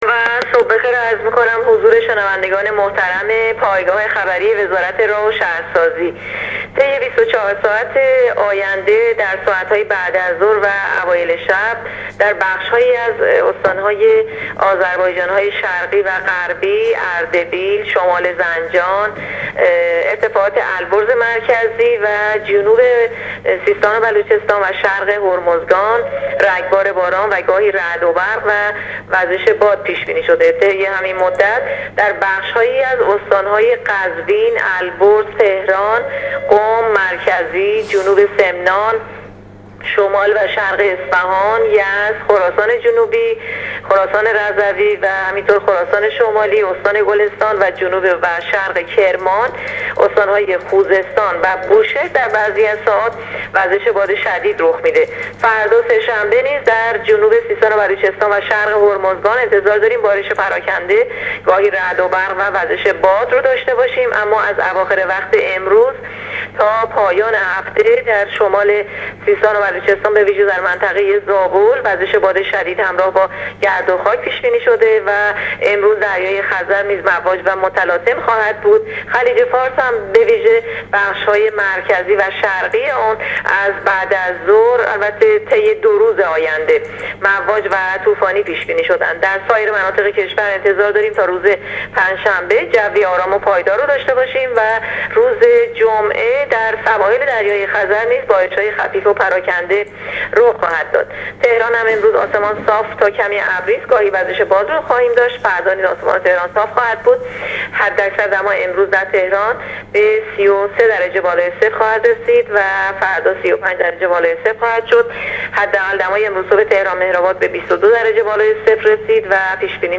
گزارش رادیو اینترنتی از آخرین وضعیت آب و هوای۲ تیر